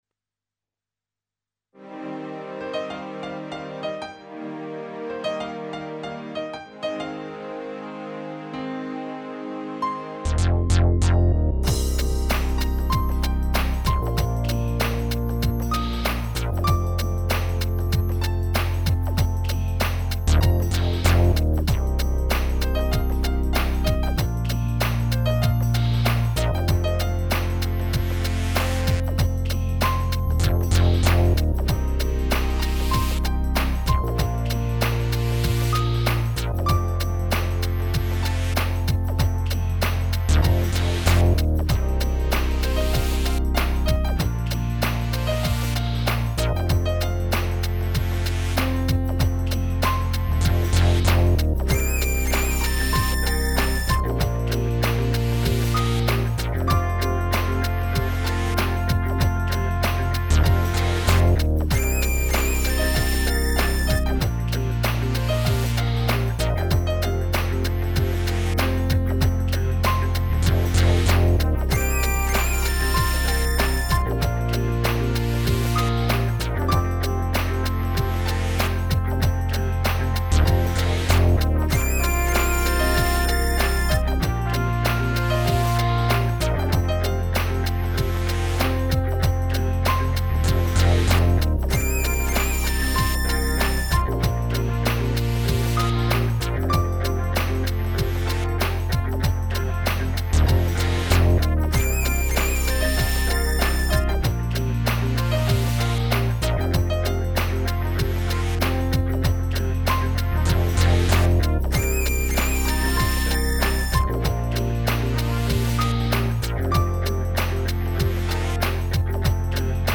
No singing in this one. It was mainly around a loop and I was excited by funkyness of the drum sample I used. Then I layered it with stuff, including some trumpet samples.
But I think I tried to keep it interesting by changing the layers of things that were playing on it.